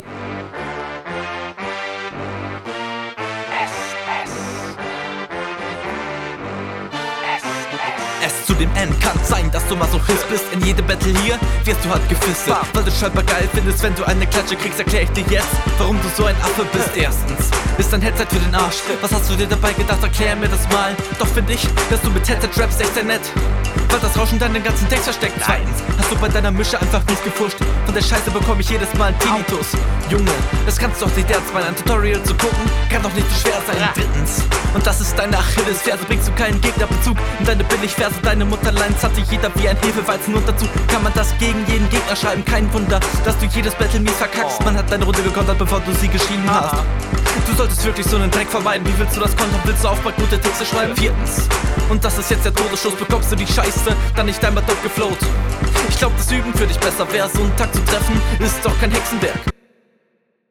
Zwischendurch bist du ein wenig offbeat, weil du versuchst zu viele Silben in die Zeile …
Netter Beat und Stimmeinsatz okay.